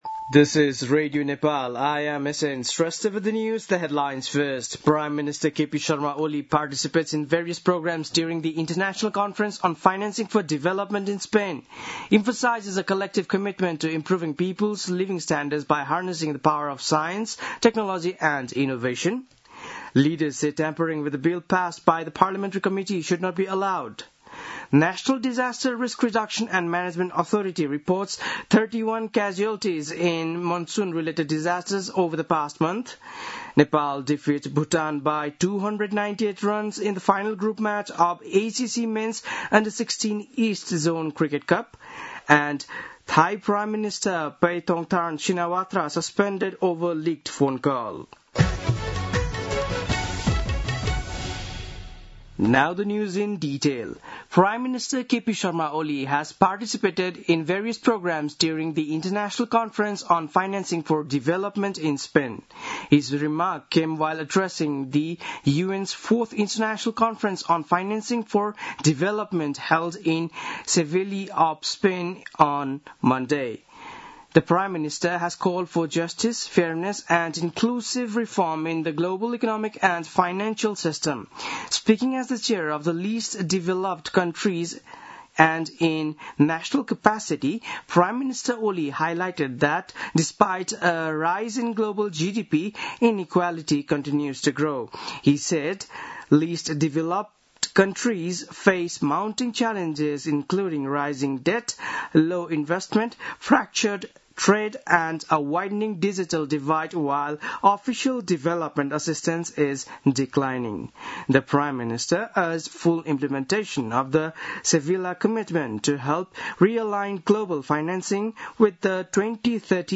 An online outlet of Nepal's national radio broadcaster
बेलुकी ८ बजेको अङ्ग्रेजी समाचार : १७ असार , २०८२